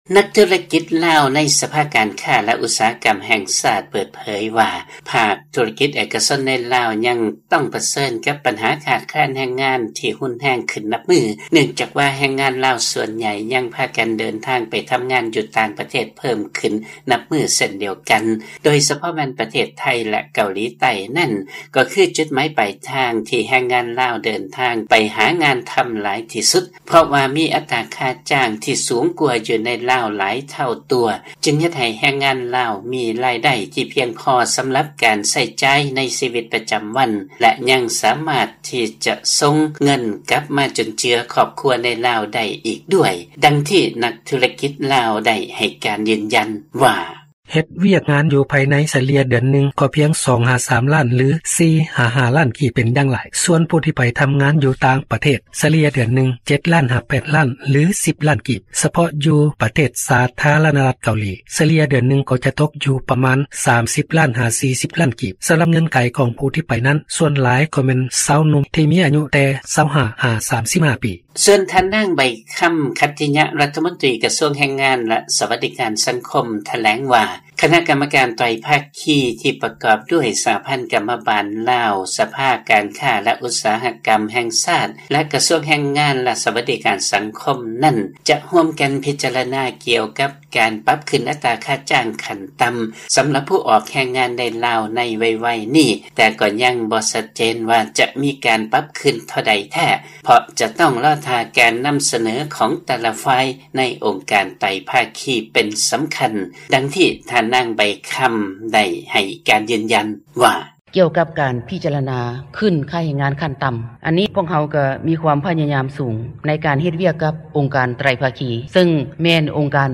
ມີລາຍງານກ່ຽວກັບເລື້ອງນີ້ ຈາກບາງກອກ.